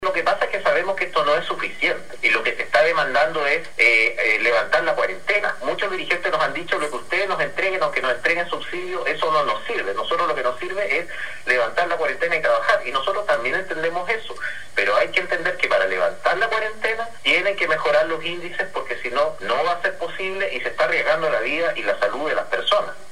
El gobernador de Concepción, Julio Anativia, señaló que pese un posible apoyo económico para el comercio más afectado, no será suficiente.